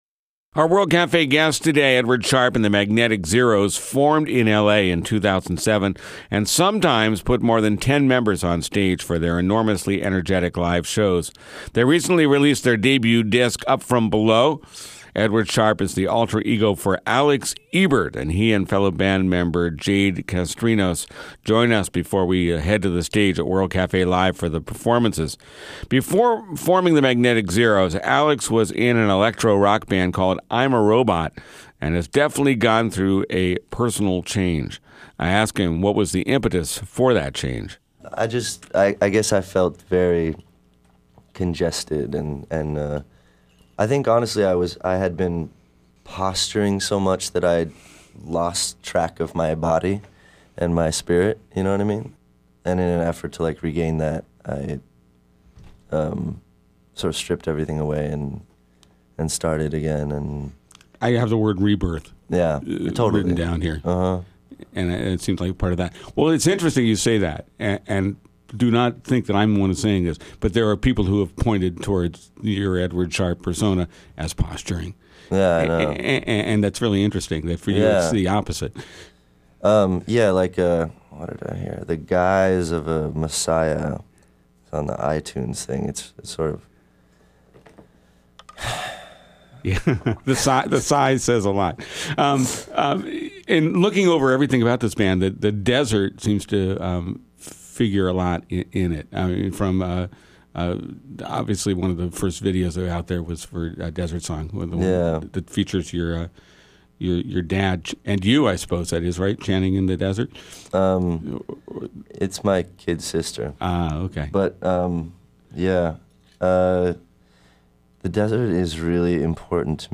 Edward Sharpe's Psychedelic Folk Troupe
They're a big outfit as indie bands go, but the 13-piece Edward Sharpe and the Magnetic Zeros have created quite a buzz in Los Angeles, and in the indie world writ large, making excellent '60s-style folk-rock that could be called revivalist. With their converted school/tour bus, their back porch acoustics and their almost communal air, this is one band not to miss.